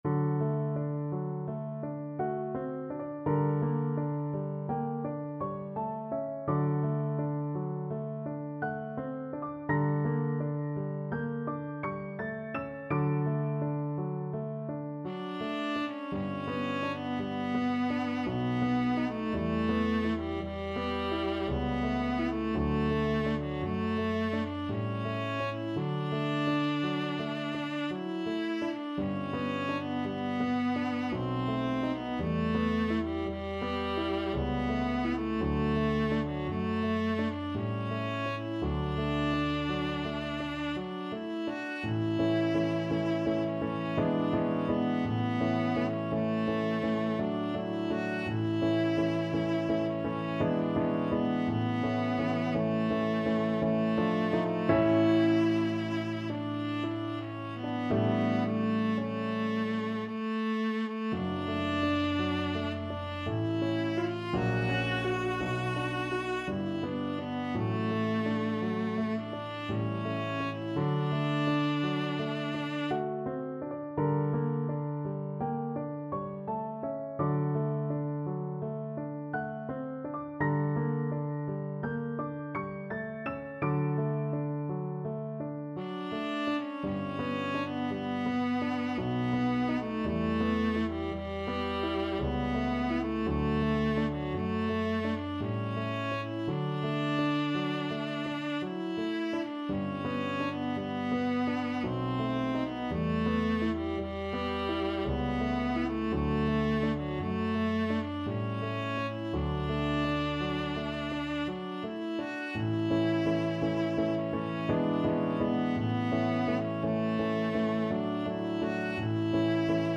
Viola
3/4 (View more 3/4 Music)
D major (Sounding Pitch) (View more D major Music for Viola )
~ = 56 Ziemlich langsam
Classical (View more Classical Viola Music)